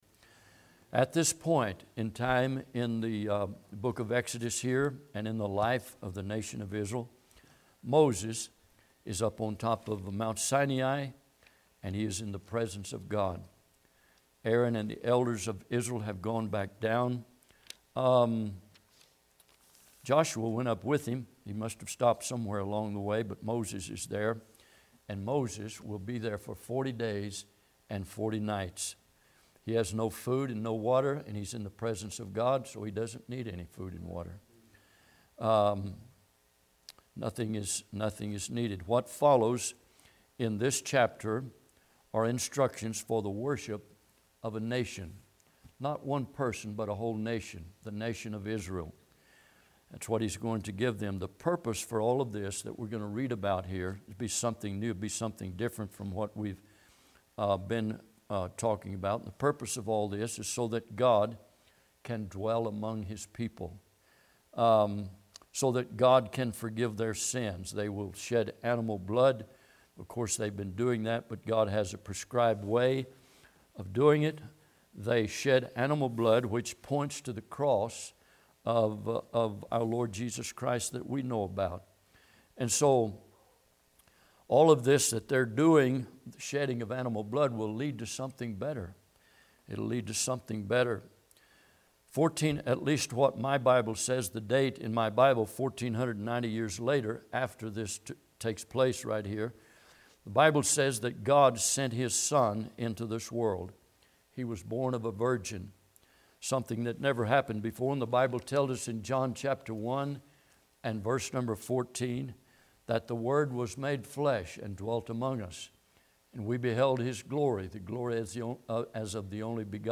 Exodus 25:1-7 Service Type: Midweek Bible Text